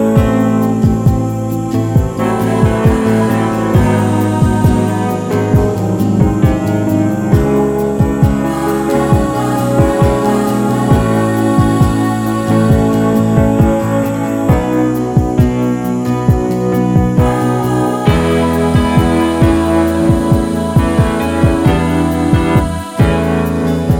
Minus Main Lead Guitars Pop (1970s) 4:53 Buy £1.50